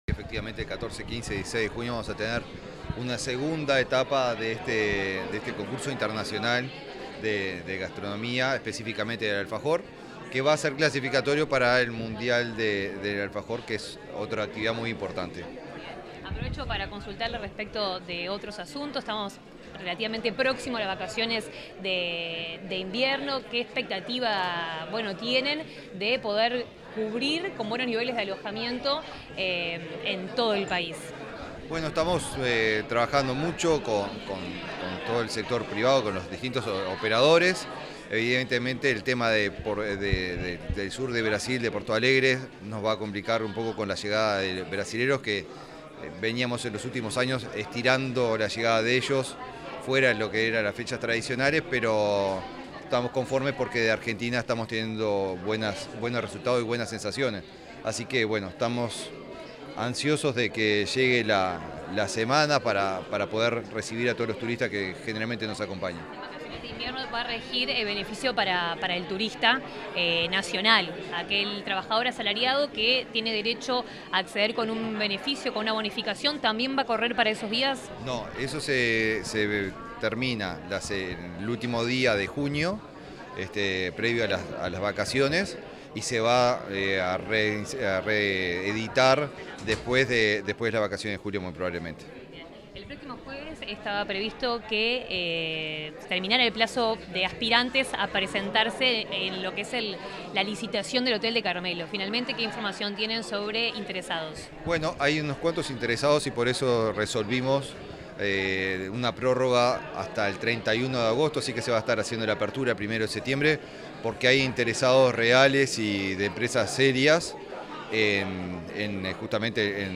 Declaraciones del ministro de Turismo, Eduardo Sanguinetti
Declaraciones del ministro de Turismo, Eduardo Sanguinetti 28/05/2024 Compartir Facebook X Copiar enlace WhatsApp LinkedIn Tras el lanzamiento de la Feria Internacional del Alfajor, que se realizará en Río Negro, este 28 de mayo, el ministro de Turismo, Eduardo Sanguinetti, realizó declaraciones a la prensa.